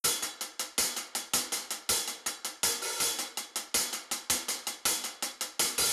Glory Hi Hat.wav